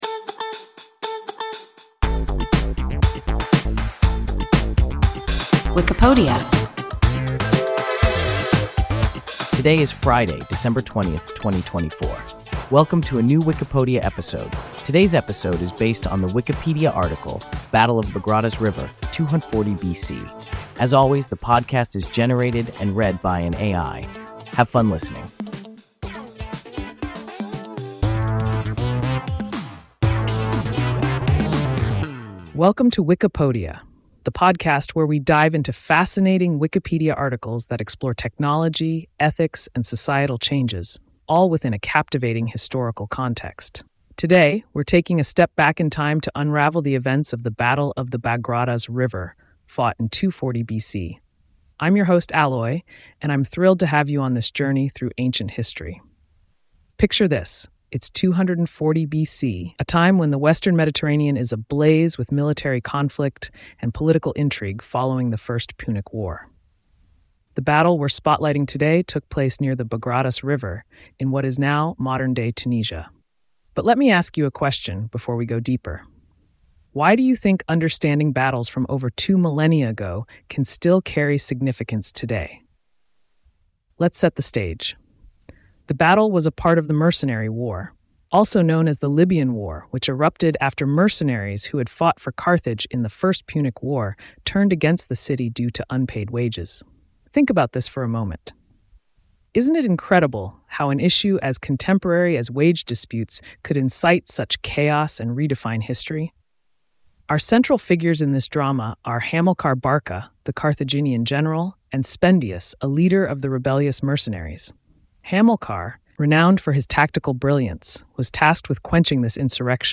Battle of the Bagradas River (240 BC) – WIKIPODIA – ein KI Podcast